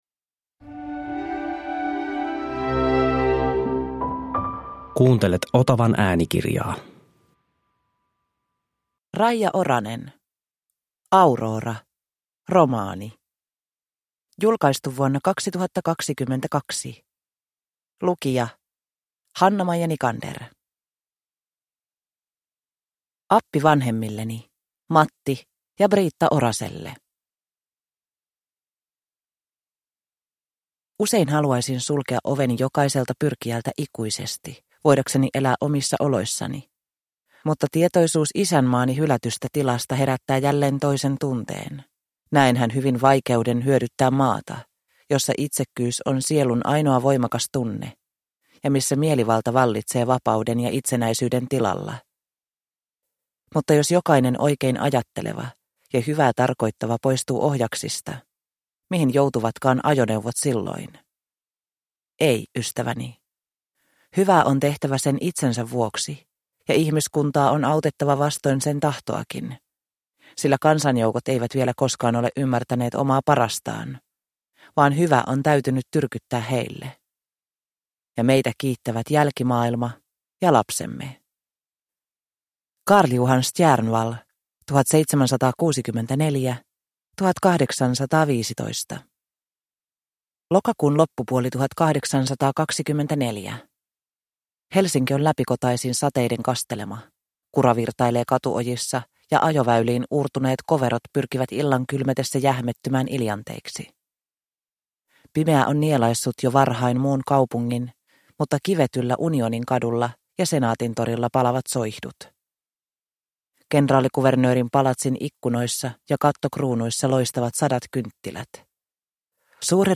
Aurora – Ljudbok – Laddas ner